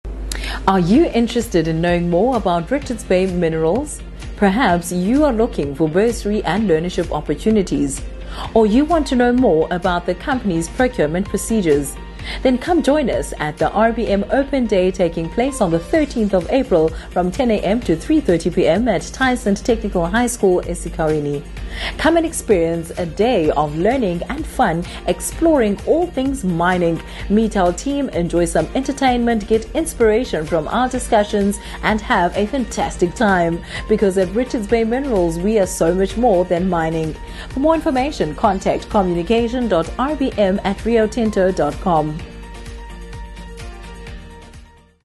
authentic, authoritative, soothing
RBM advert demo